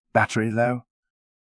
battery-low.wav